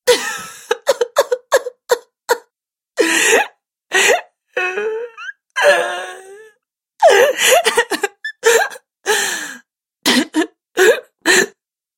Звуки женского плача
Горестный плач девушки